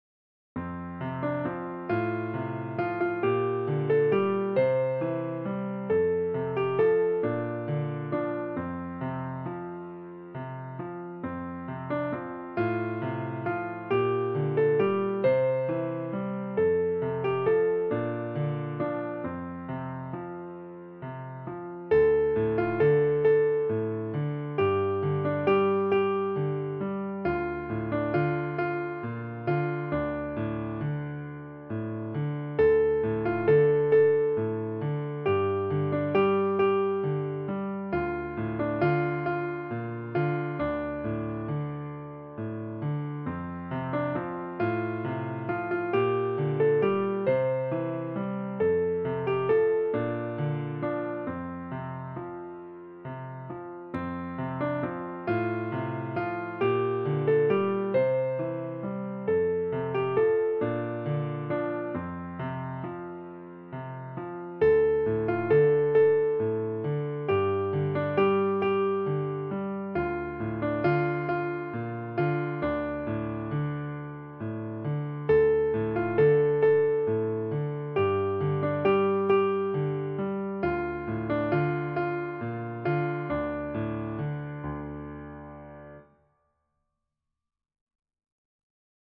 a traditional Scottish Folk Song, arranged for piano